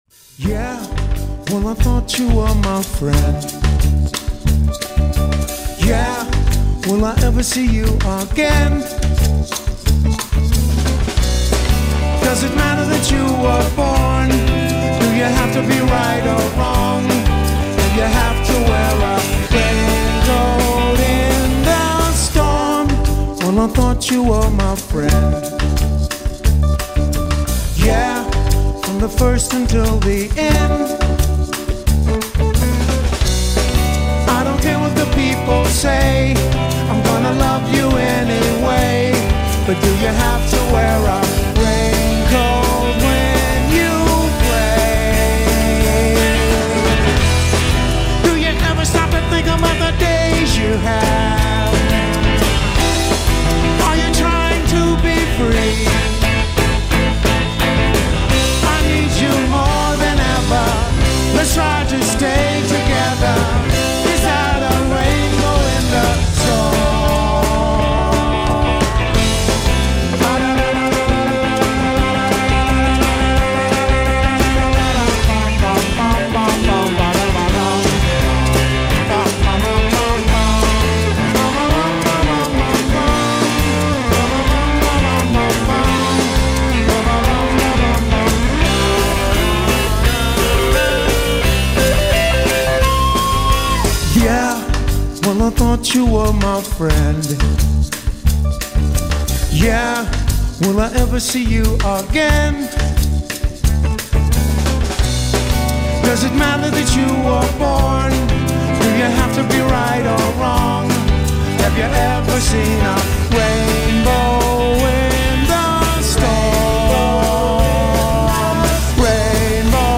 one of the truly unique and evocative voices